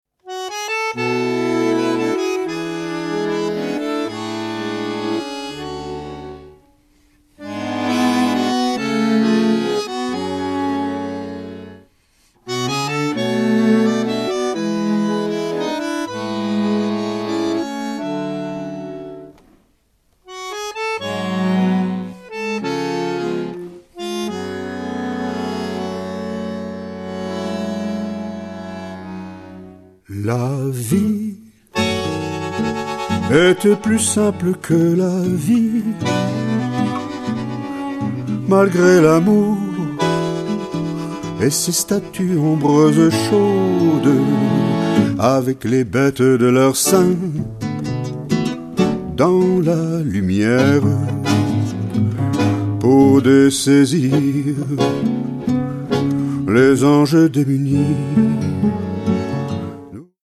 chant, guitare
violoncelle